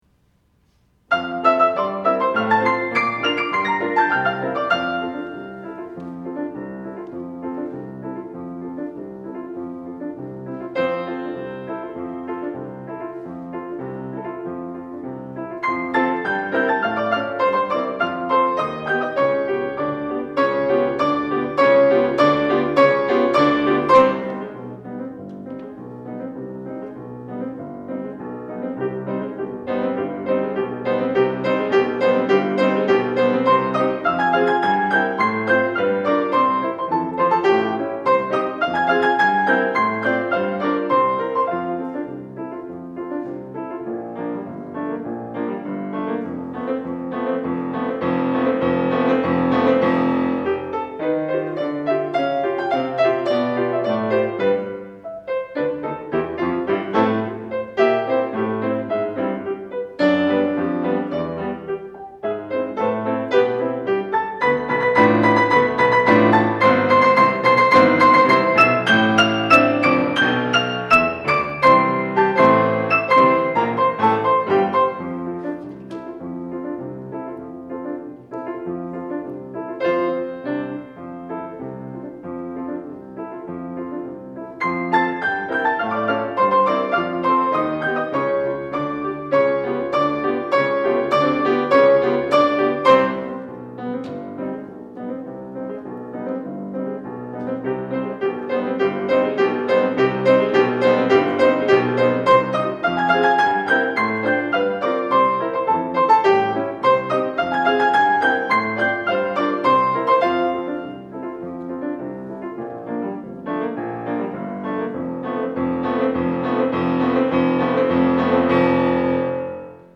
piano
Period Early 20th century Piece Style Early 20th century Instrumentation orchestra External Links Wikipedia article Classical Archives article All Music Guide Extra Information Arranged same year for piano duet.